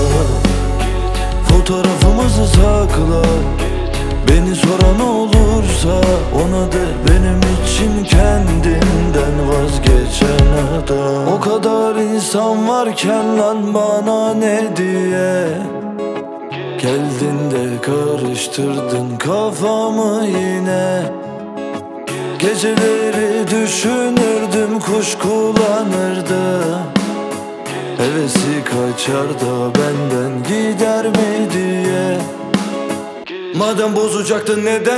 Turkish Hip-Hop Rap
Жанр: Хип-Хоп / Рэп